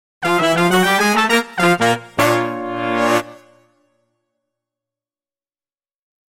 Звуки завершения, эффект
Звук продолжение следует